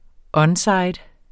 Udtale [ ˈʌnˌsɑjd ]